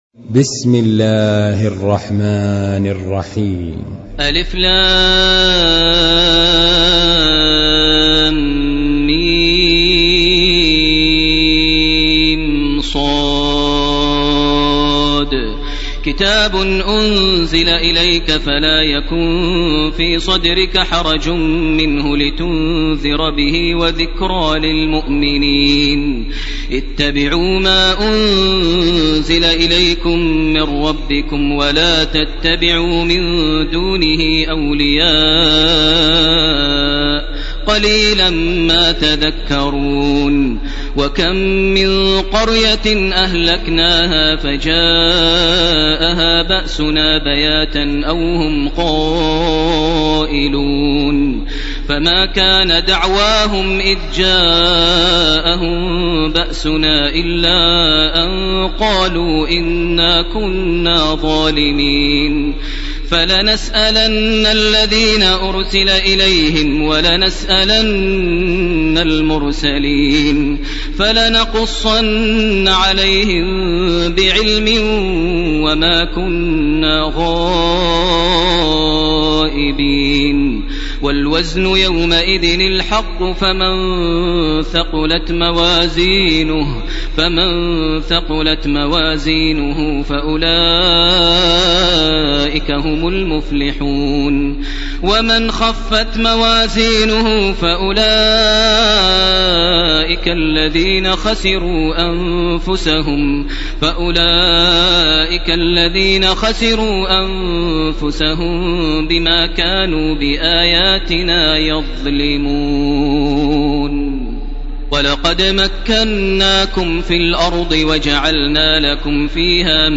> تراويح ١٤٣١ > التراويح - تلاوات ماهر المعيقلي